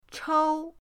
chou1.mp3